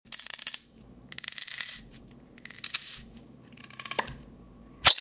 ASMR quick cuts! Glass strawberry.